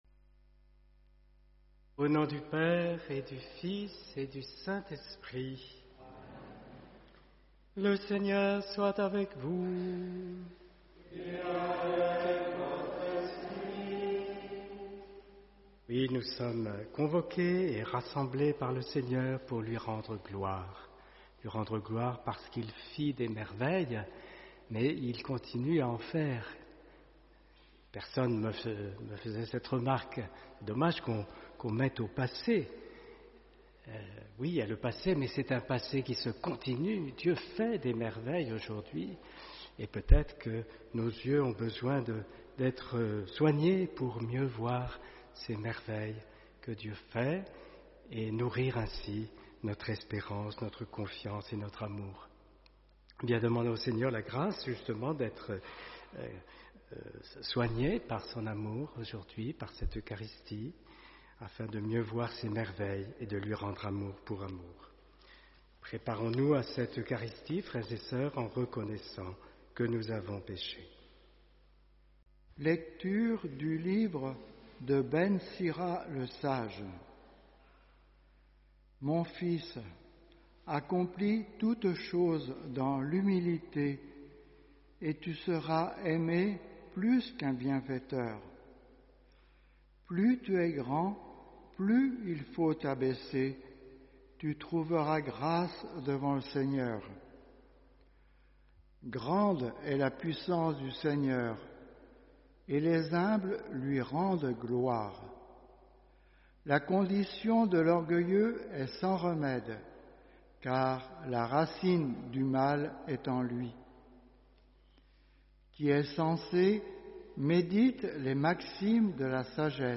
Homélie « Admirable grandeur, étonnante bonté du Maître de l’univers qui s’humilie pour nous.